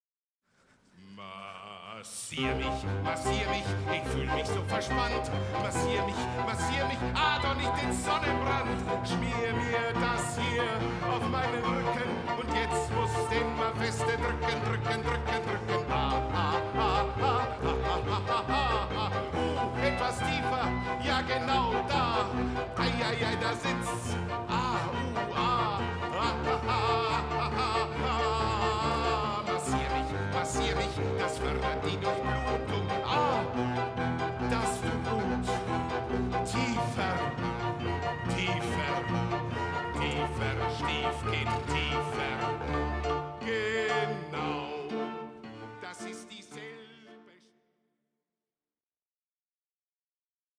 Münchner Erstaufführung am 17. Januar 2015 in der Reithalle
Musiker des Orchesters des Staatstheaters am Gärtnerplatz